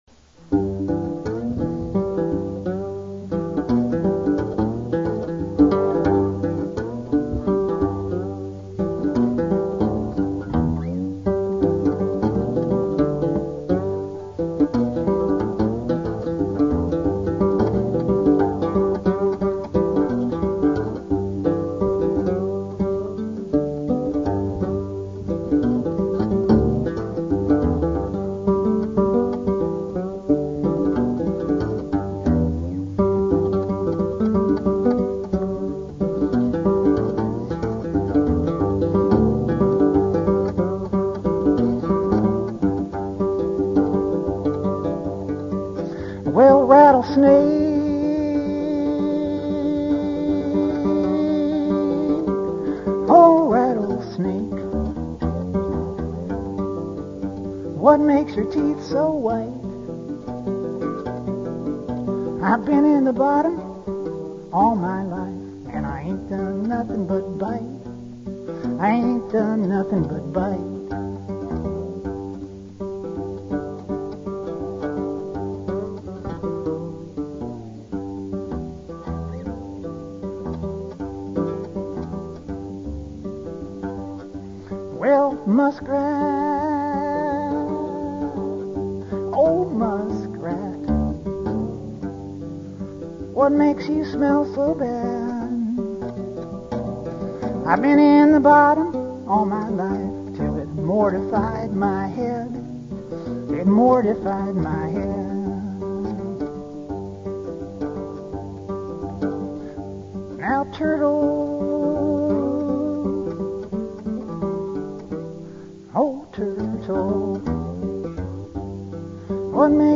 Rattlesnake" from his lecture at the 2004 GAL Convention